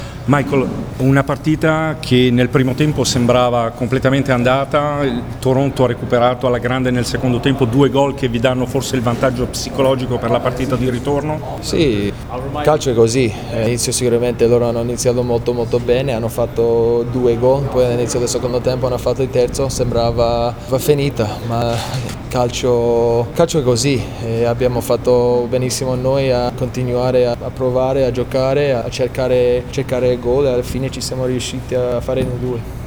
Le interviste del post partita: